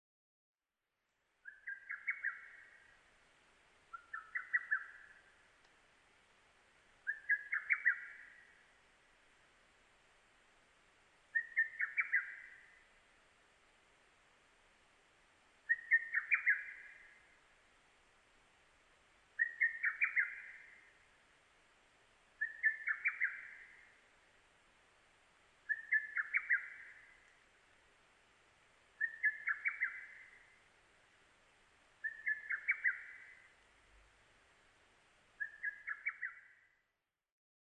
ホトトギス　Cuculus poliocephalusカッコウ科
日光市稲荷川中流　alt=730m  HiFi --------------
Mic.: built-in Mic.